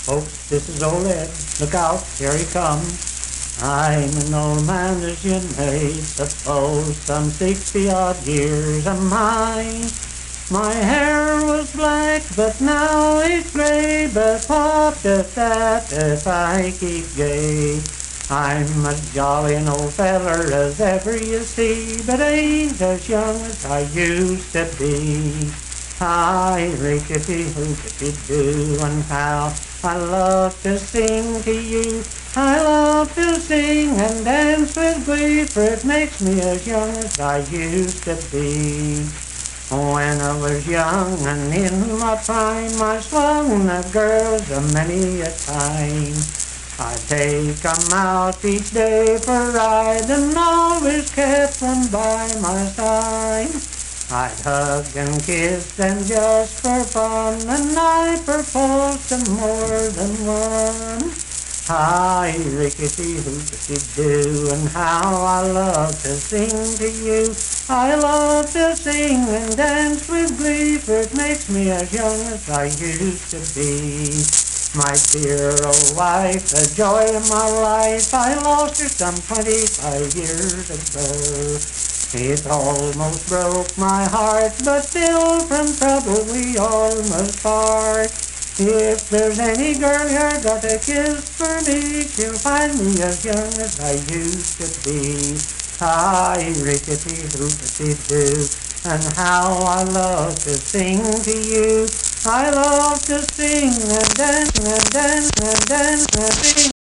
Unaccompanied vocal music
Verse-refrain 3(4)&R(4).
Performed in Dryfork, Randolph County, WV.
Voice (sung)